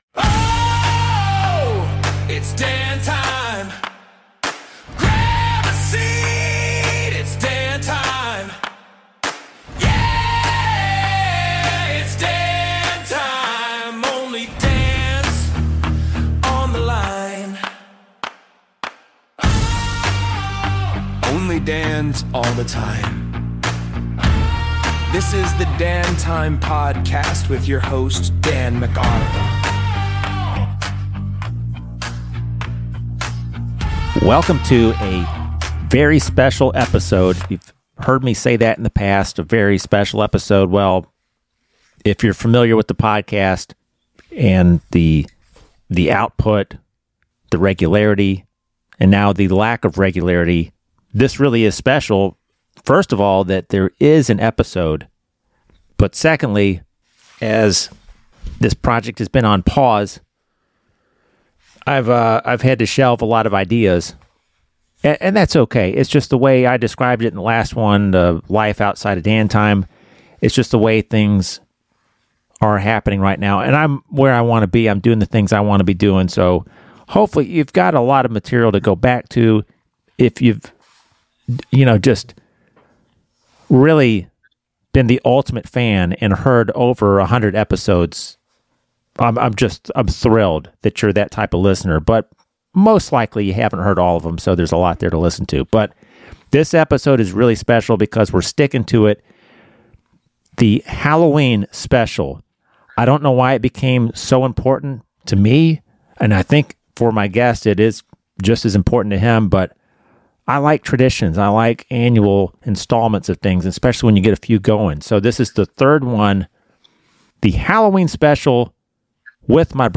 Enjoy PART I of our conversation - centered around memories and favorite songs from the late great Ace Frehley, original lead guitarist for KISS.